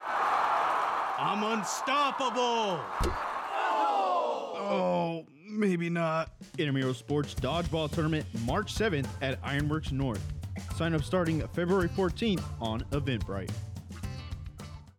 A 30-second radio spot production